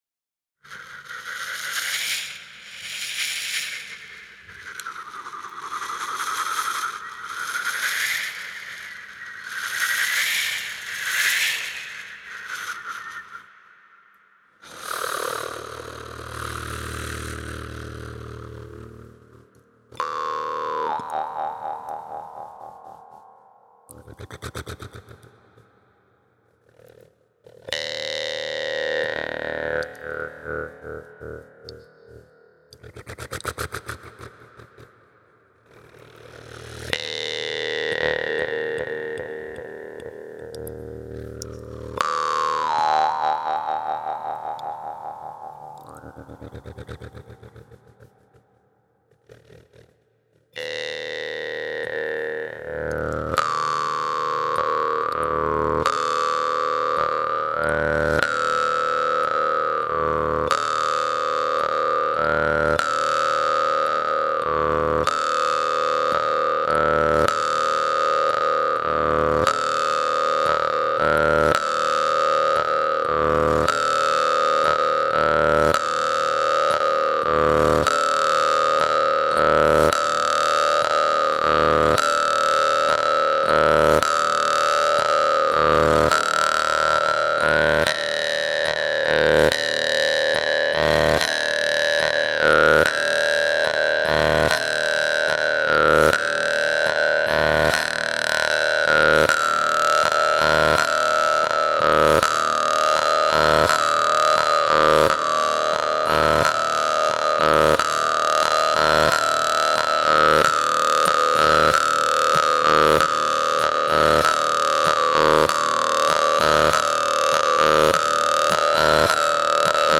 Jew's-Harp
Genre: Electronic.